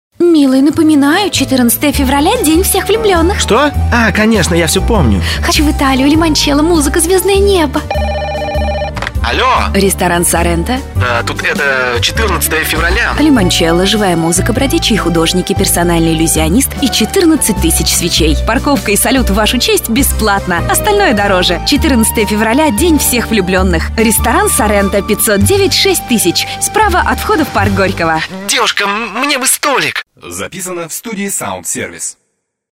2) Аудиоролик «Драматизация» - это "сценка из жизни", в которой проблема решается с помощью рекламируемого продукта или услуги.
Драматизация отличается от диалога тем, что в ней используются профессиональные актеры, музыка, эффекты, требует репетиций.